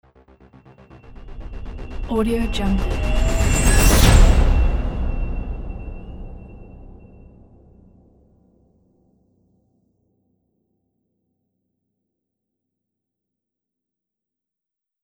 دانلود افکت صوتی رایزر سینمایی 6